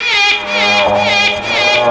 Index of /90_sSampleCDs/Houseworx/12 Vocals/74 Processed Vocal Loops